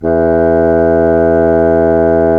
Index of /90_sSampleCDs/Roland L-CDX-03 Disk 1/CMB_Wind Sects 1/CMB_Wind Sect 6
WND BSSN E2.wav